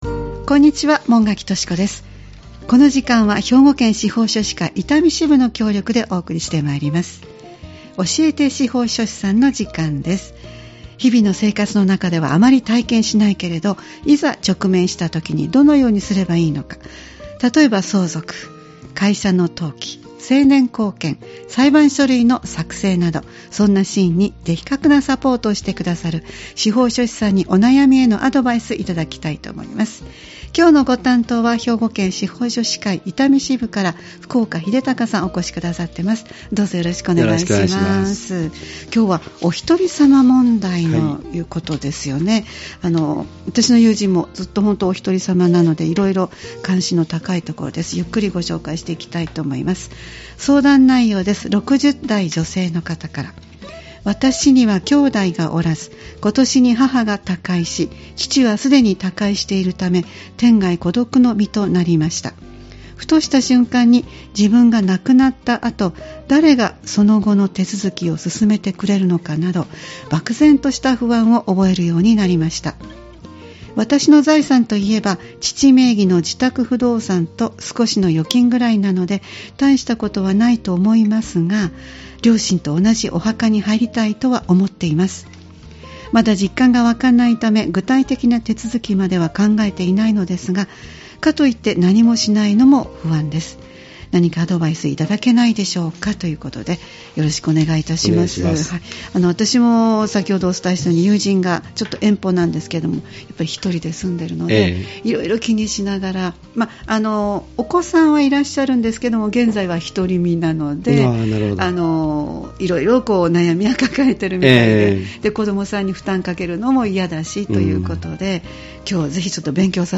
毎回スタジオに司法書士の方をお迎えして、相続・登記・成年後見・裁判書類の作成などのアドバイスを頂いています。